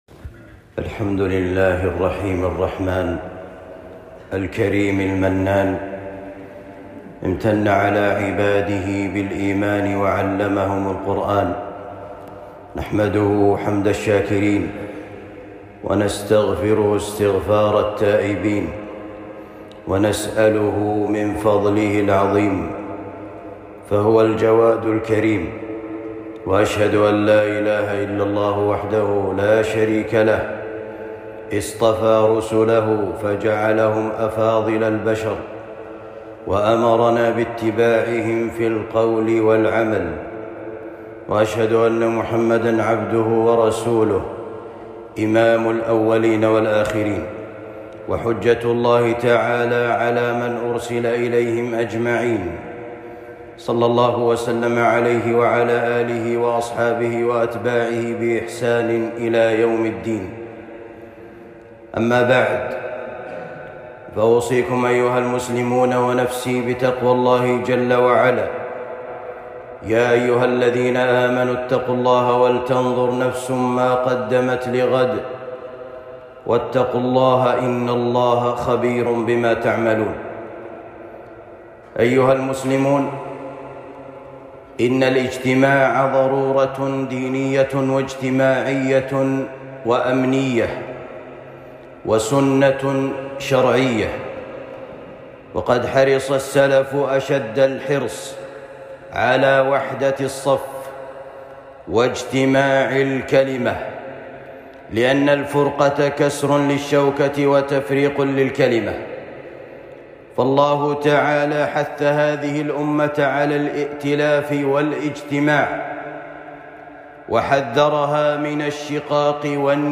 الموجز البليغ في بيان أخطاء جماعة التبليغ خطبة جمعة